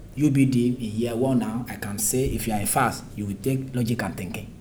S1 = Bruneian female S3 = Nigerian male Context : S3 is talking about how practical the courses are in UBD, particularly those offered by FASS (the Faculty of Arts and Social Sciences).
We might also note that the vowel in take is a close front monophthong. Indeed, the frequency of the first formant is 313 Hz and that of the second formant is 2473 Hz, values that are typical of a close front vowel such as [ɪ] .